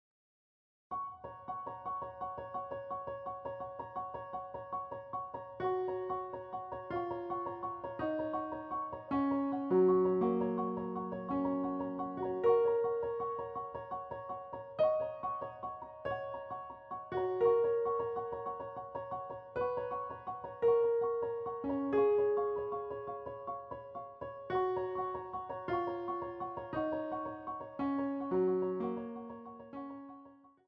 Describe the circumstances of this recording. A quality audio recording of an original piano roll